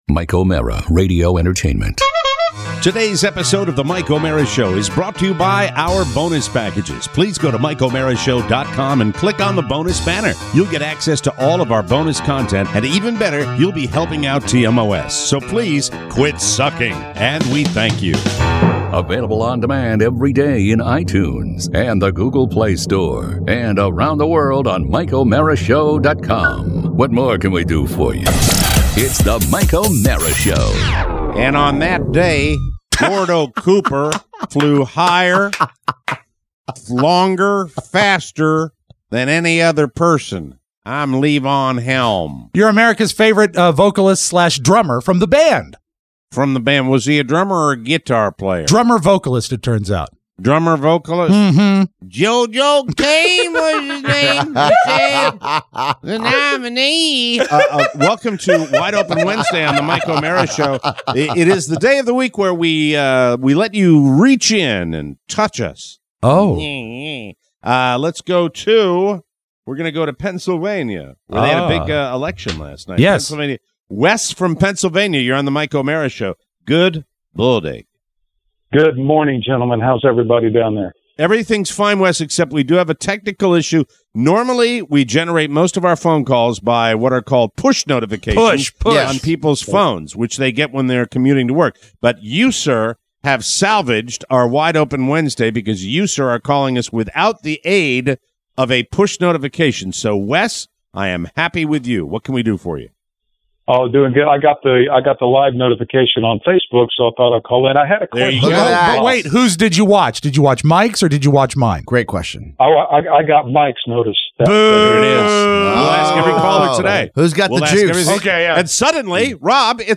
Your wonderful calls! Plus… dental implants, Dr Hawking, poison grass… and a new boyfriend.